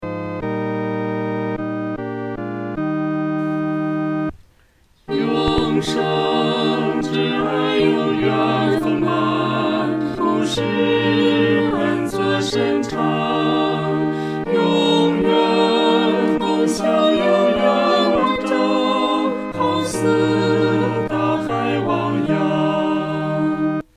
四声